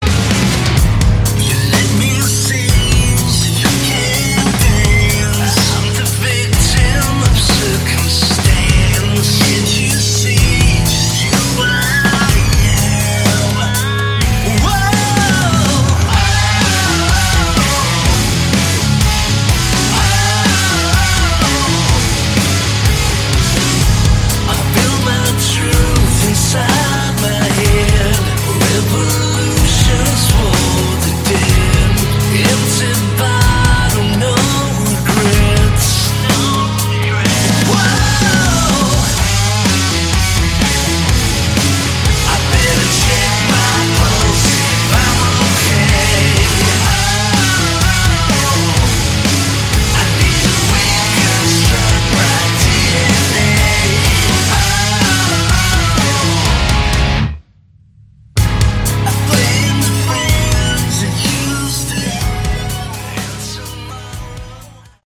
Category: Hard Rock
guitars
vocals
drums